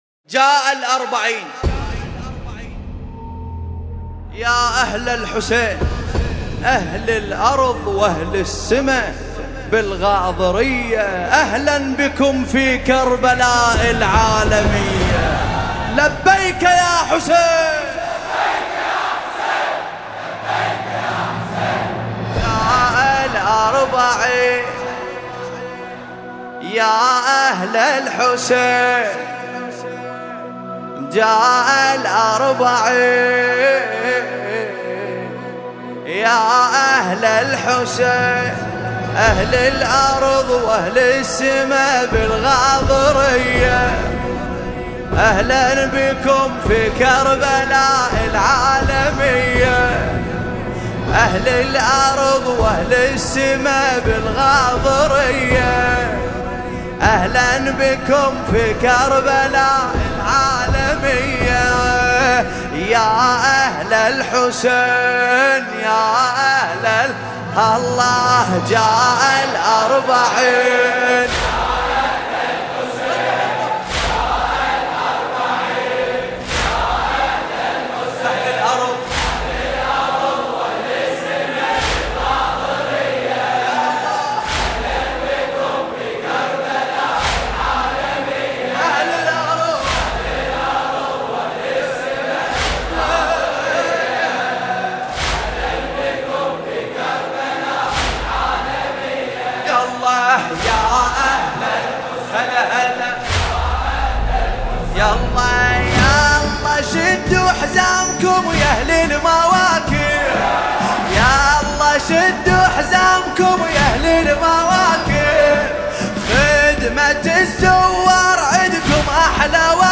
مداحی_اربعین حسینی